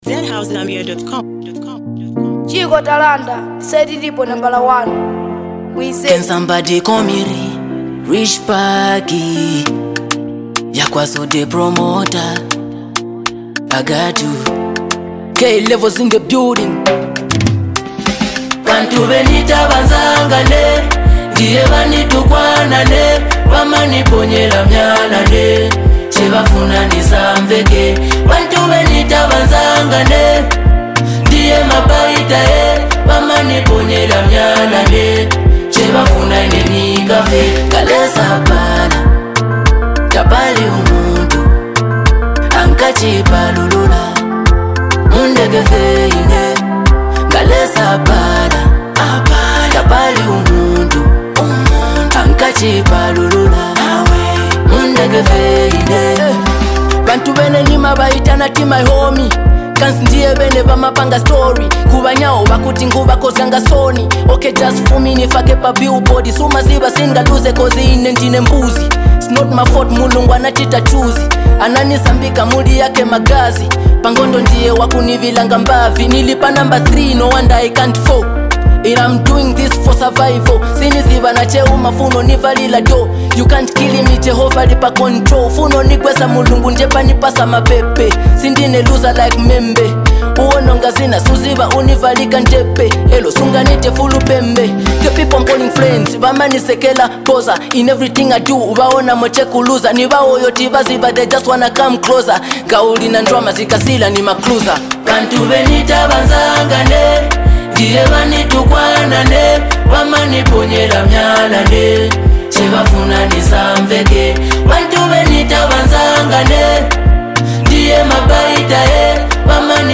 A powerful anthem of resilience!